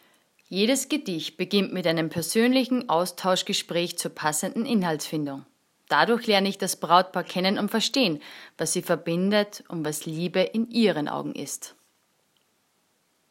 Vor kurzem ist Life Radio auf mich zugekommen und hat mich zu meinen Hochzeitsgedichten befragt.